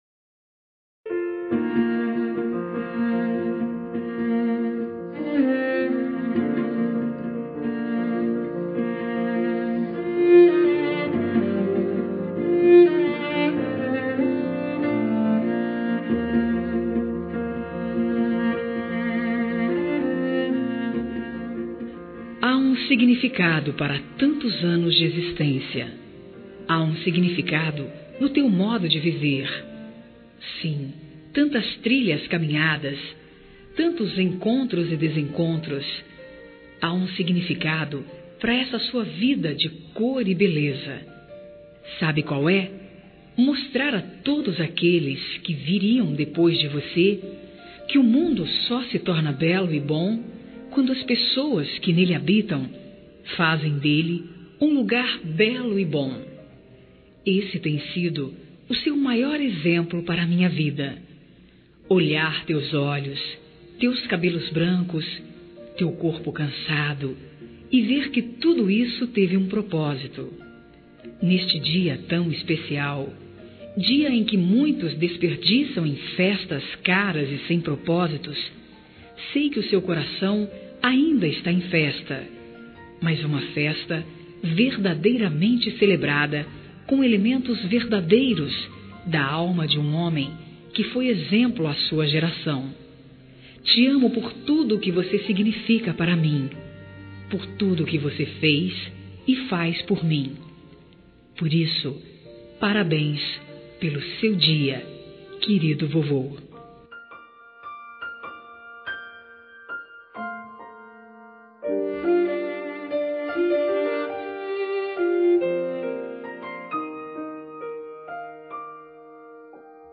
Aniversário de Avô – Voz Feminina – Cód: 4250